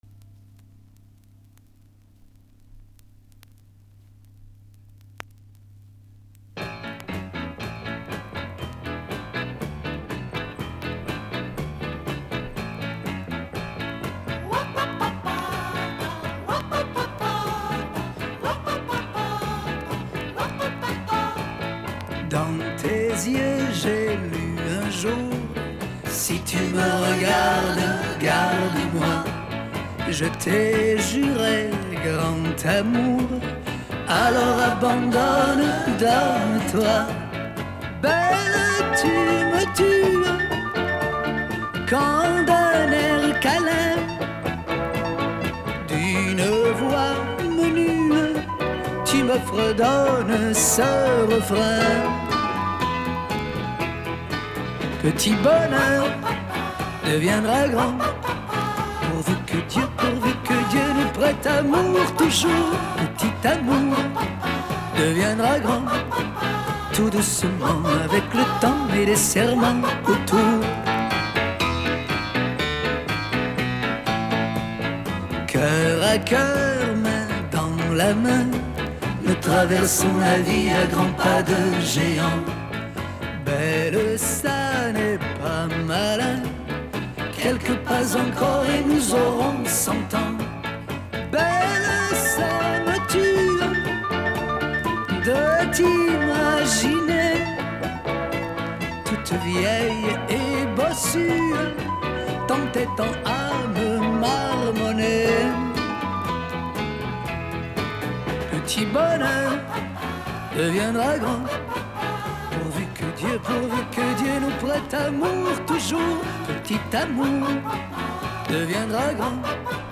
Другая запись этой песни вроде бы в лучшем качестве.